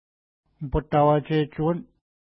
ID: 344 Longitude: -60.1458 Latitude: 53.5233 Pronunciation: upəta:wa:tʃetʃu:wən Translation: Rapids in Sand Narrows Official Name: North West River Feature: narrows Explanation: Old name, not used any more but known by the Elders.